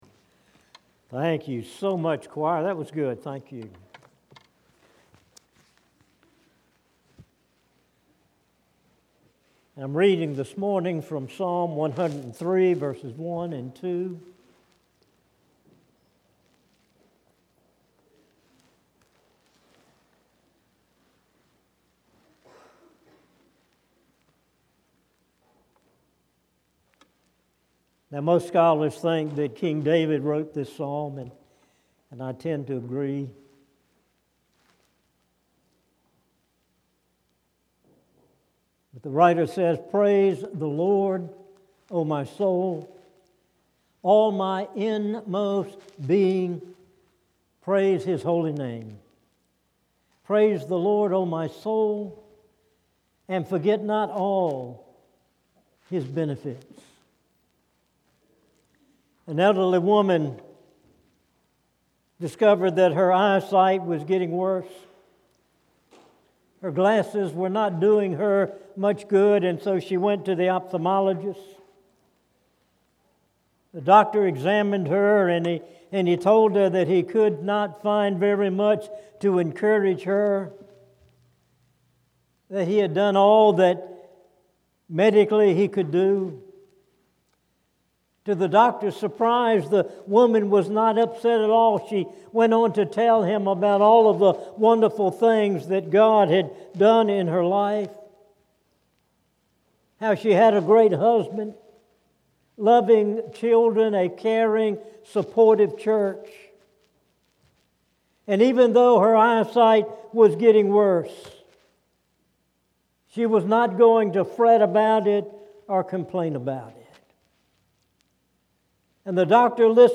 Tramway Baptist Church Sermons